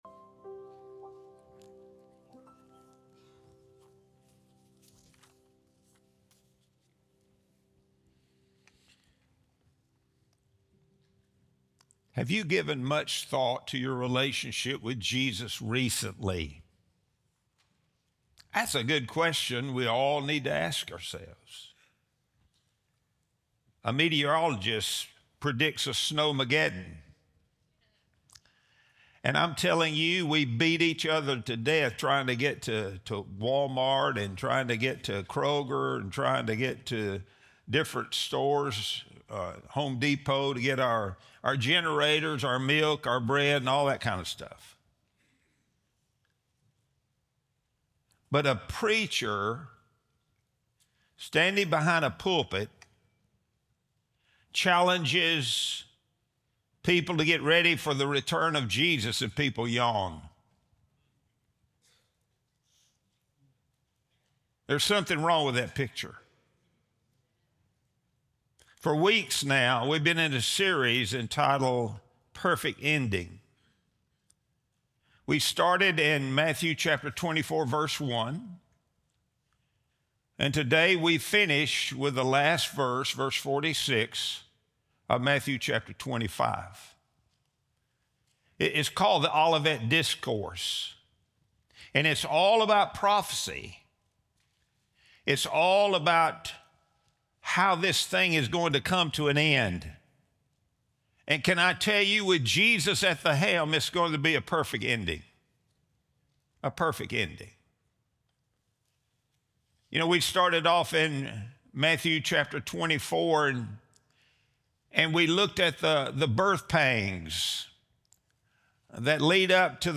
Sunday Sermon | February 1, 2026